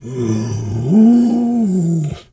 bear_roar_soft_02.wav